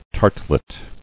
[tart·let]